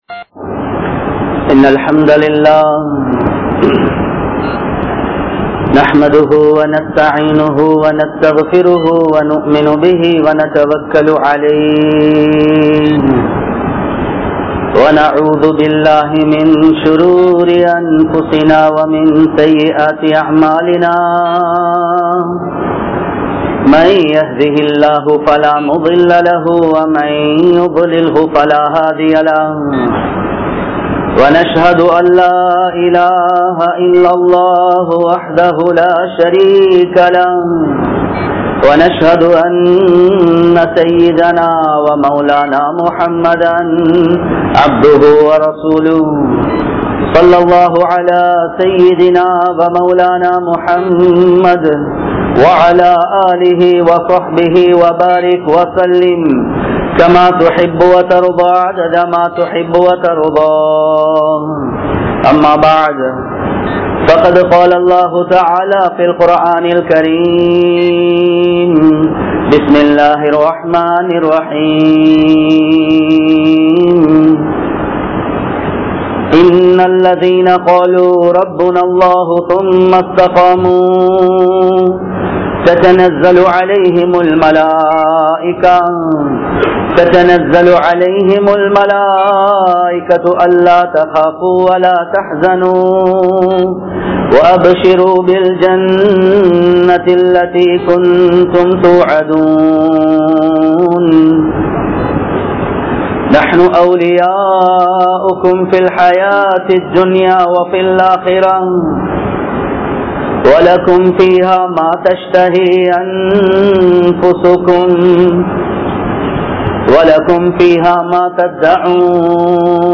Veattrumaiel Ottrumaiyaaha Vaalvoam (வேற்றுமையில் ஒற்றுமையாக வாழுவோம்) | Audio Bayans | All Ceylon Muslim Youth Community | Addalaichenai
Kollupitty Jumua Masjith